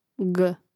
Naziv je hrvatskoga slova g ge.